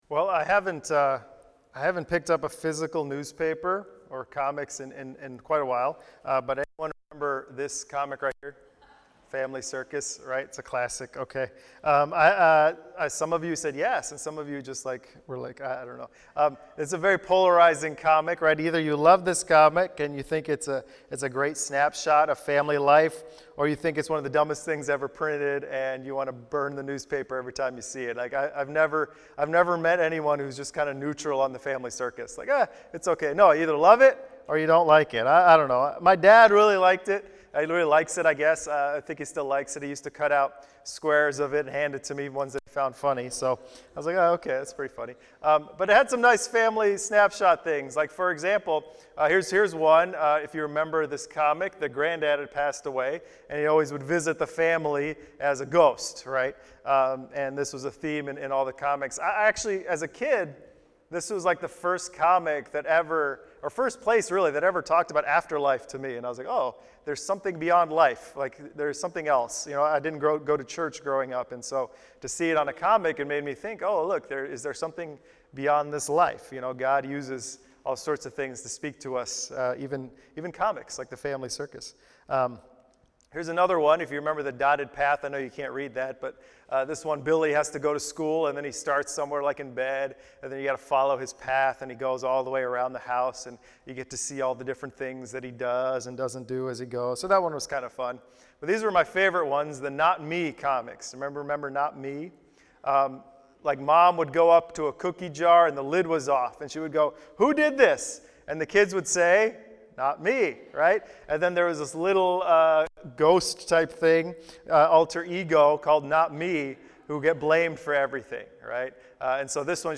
Messiah-Sermon-08-Sept-19.mp3